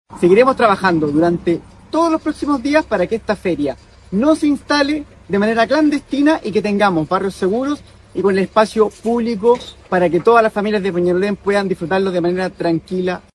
alcalde.mp3